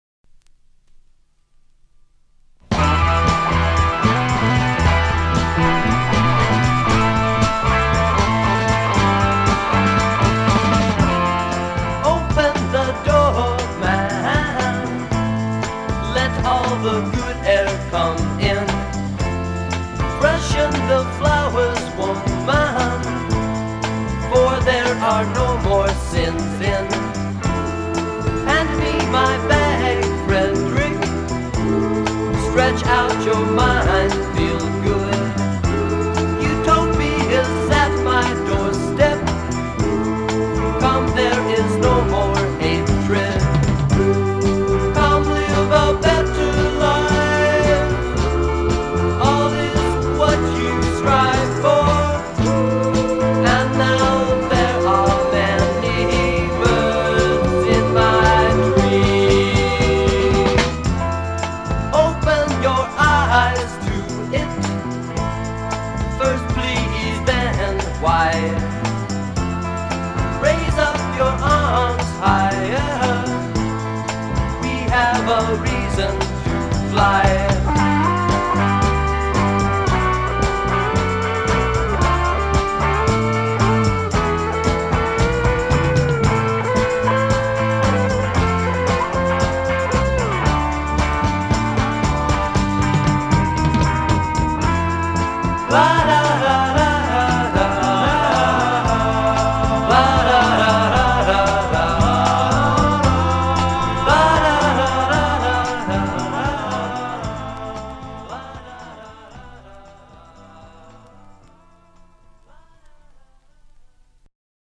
I’m a sucker for ’60s psychedelia.